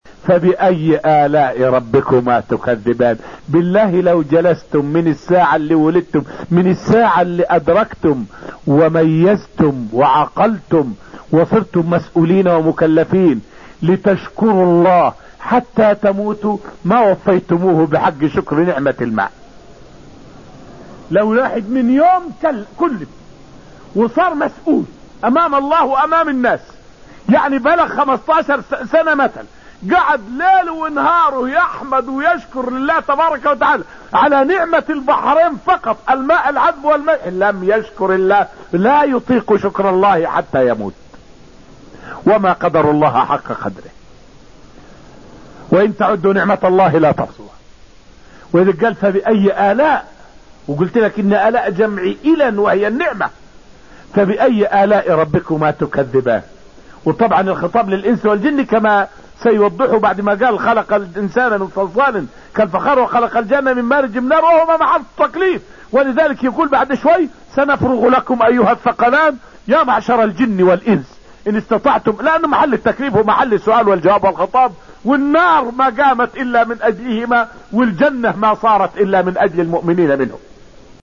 فائدة من الدرس السابع من دروس تفسير سورة الرحمن والتي ألقيت في المسجد النبوي الشريف حول معنى قوله تعالى: {فبأي آلاء ربكما تكذبان}.